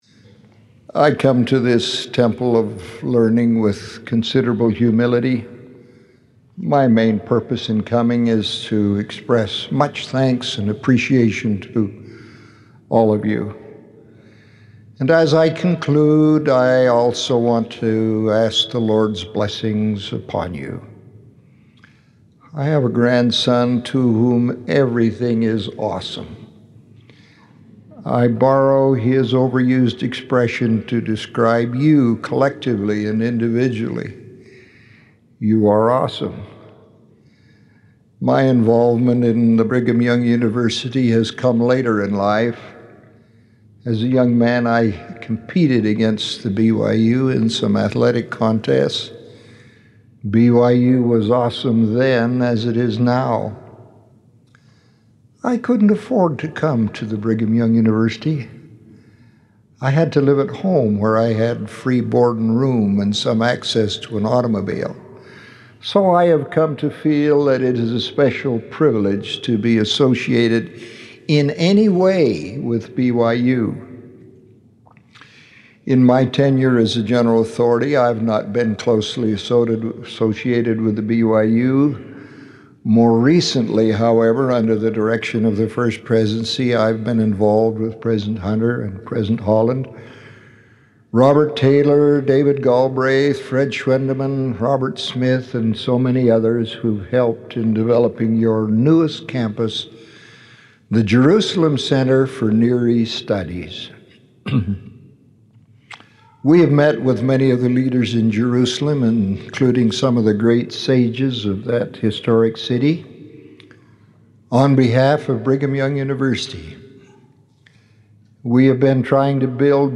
of the Quorum of the Twelve Apostles